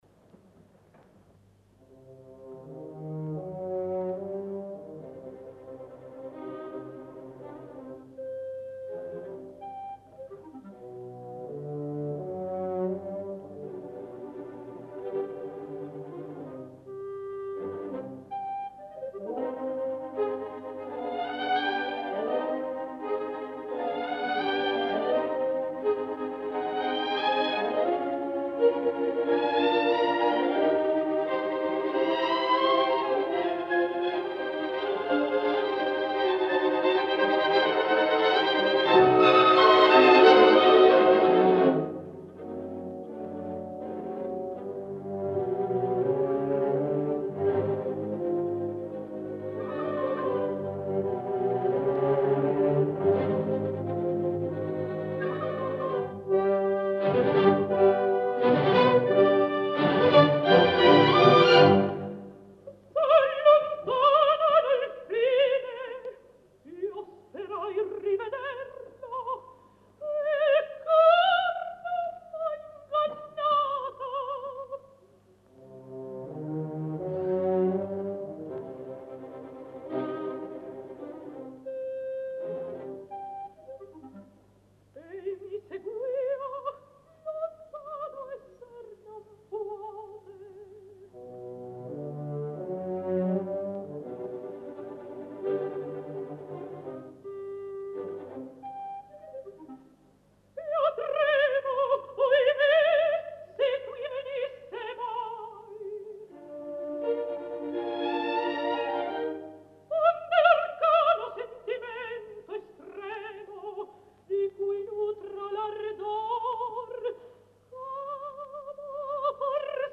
Anita Cerquetti
S’allontanano alfin…Selva opaca (dir. Mario Rossi – 1956)